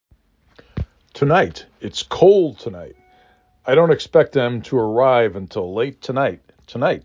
5 Phonemes
t ə n I t